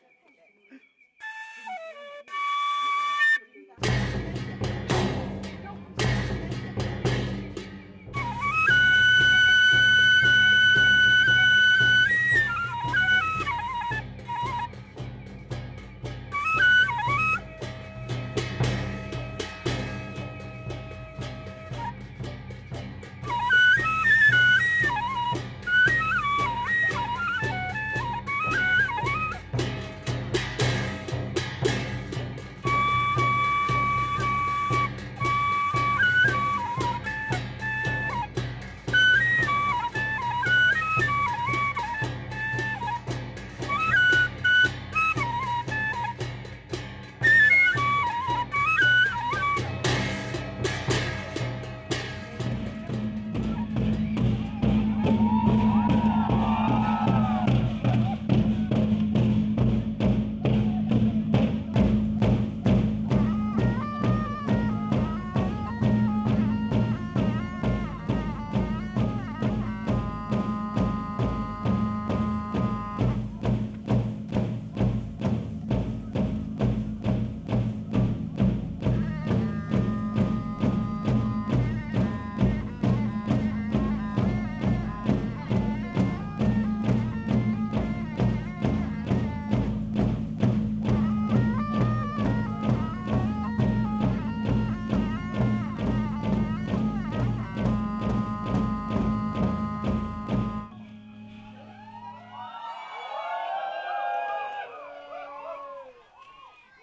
Kachin music
Manau festival music shawm, flutes and percussion accompany the dancing 1.6MB
The rhythm is regular but the melody is more elaborate and reminiscent of Middle Eastern music.
Track 44 Kachin Manau festival.mp3